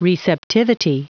Prononciation du mot receptivity en anglais (fichier audio)
Prononciation du mot : receptivity